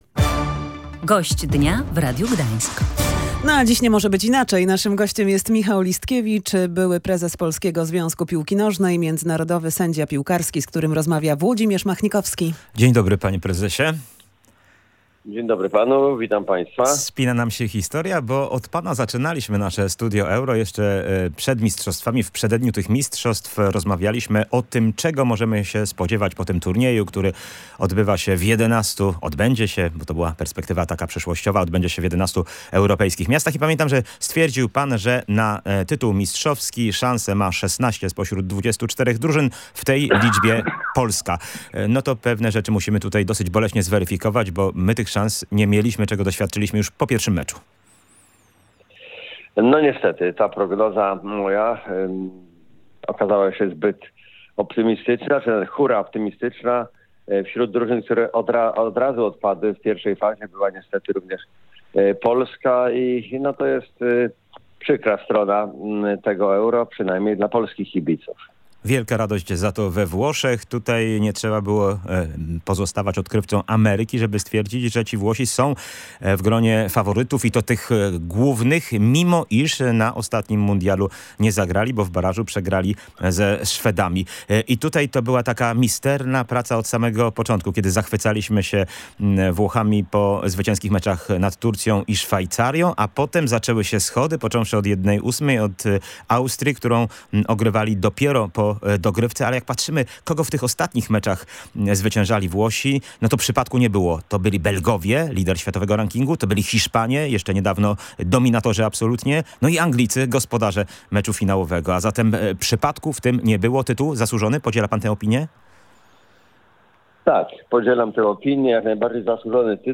pytał Gościa Dnia Radia Gdańsk